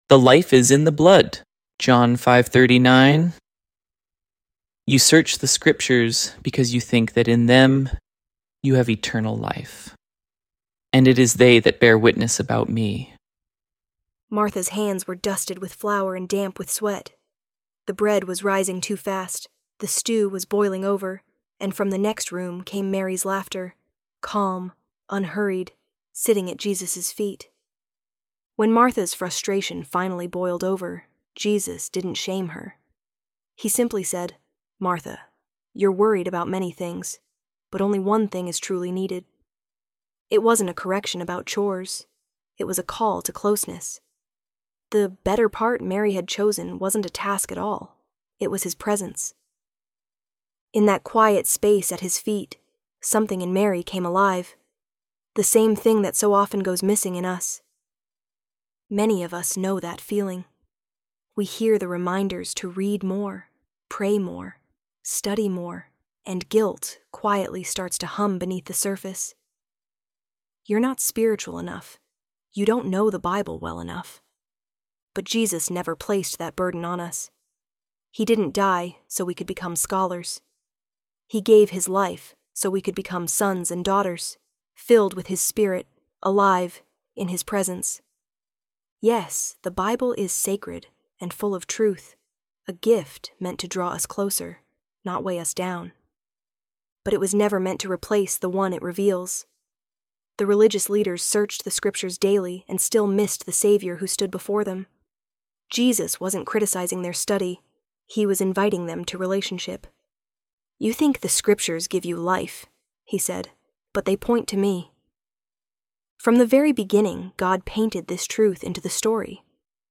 ElevenLabs_The_Life_Is_in_the_Blood.mp3